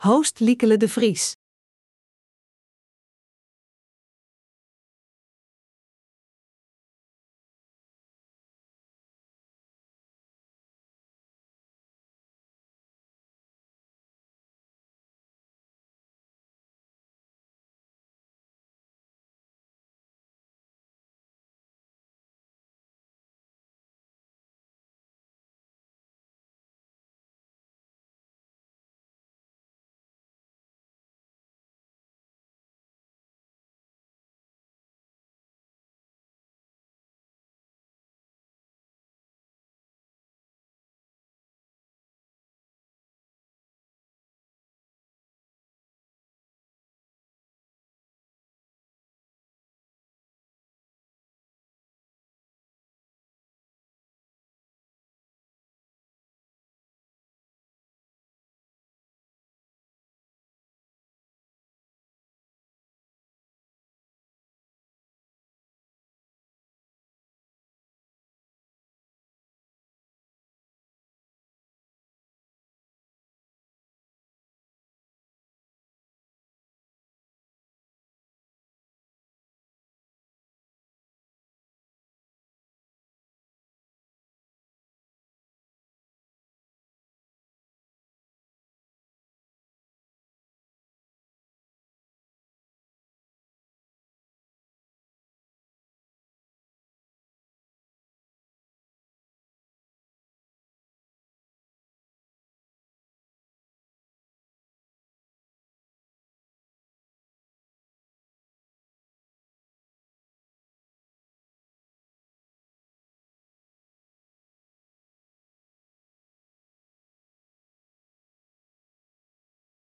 RADIO Webinar: Ontdek Neurotechnologie deel 1 over Brein-Computer Interface